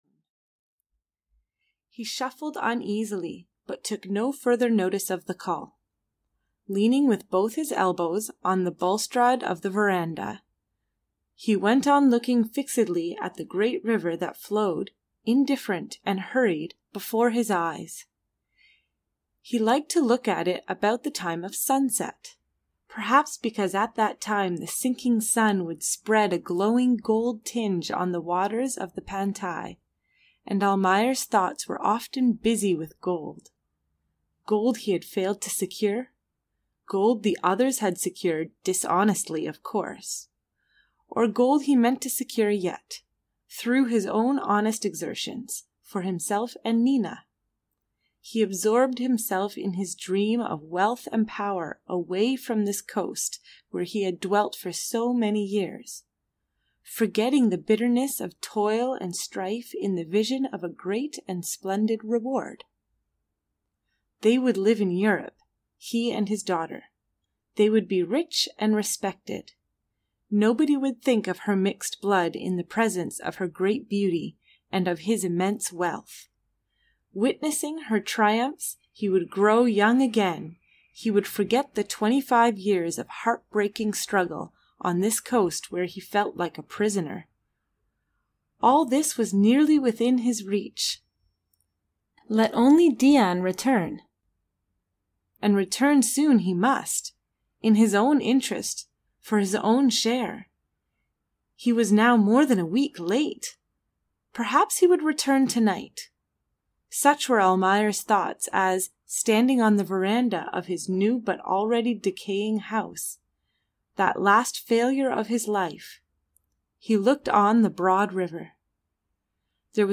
Almayer's Folly (EN) audiokniha
Ukázka z knihy